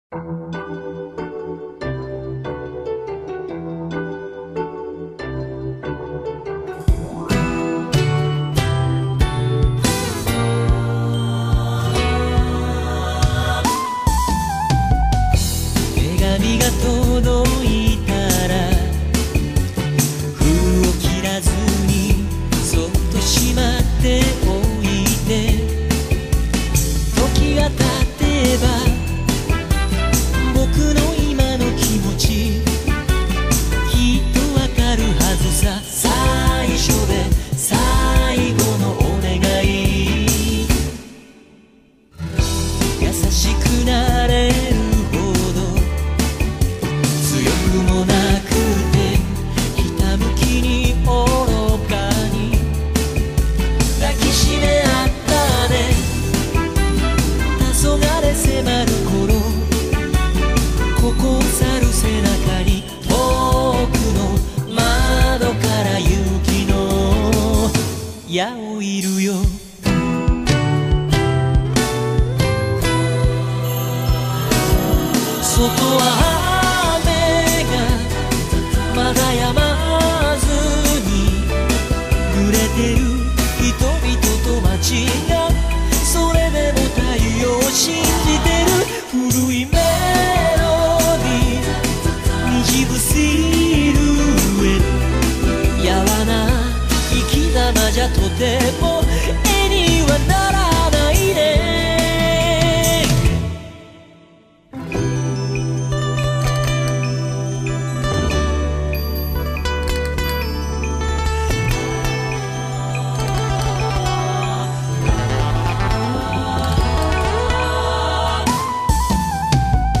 Quarta sigla di Chiusura